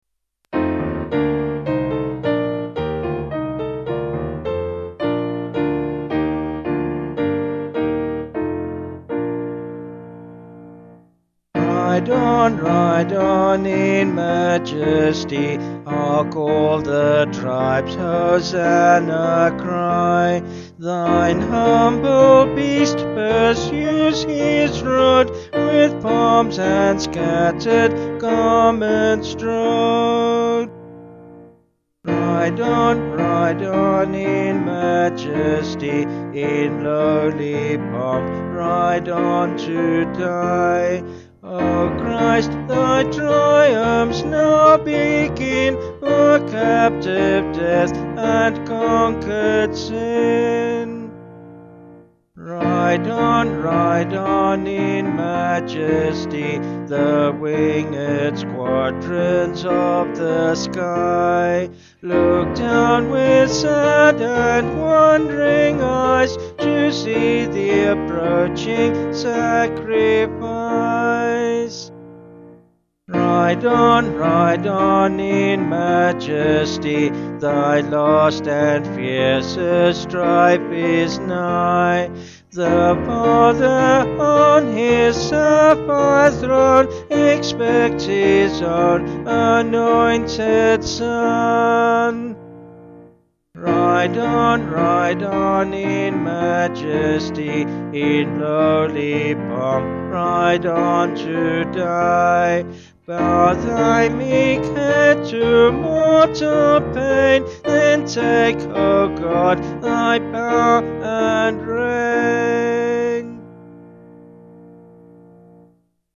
Vocals and Piano   264.6kb Sung Lyrics